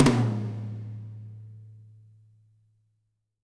-TOM 2L   -R.wav